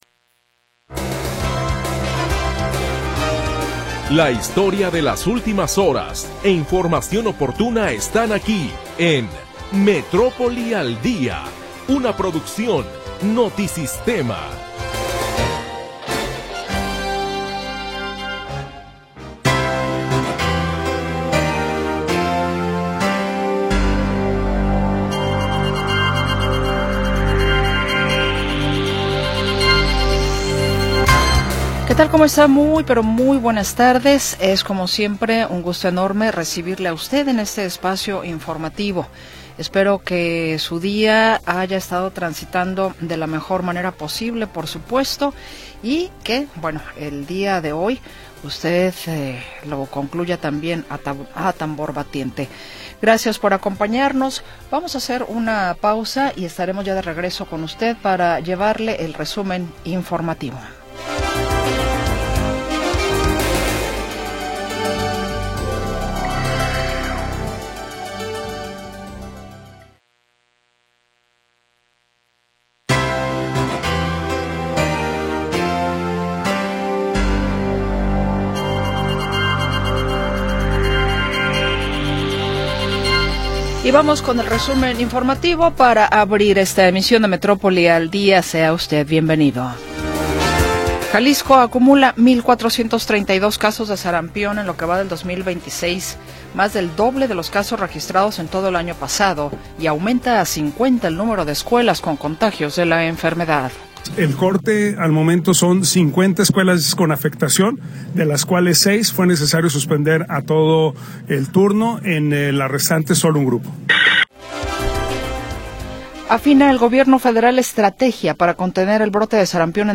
Primera hora del programa transmitido el 10 de Febrero de 2026.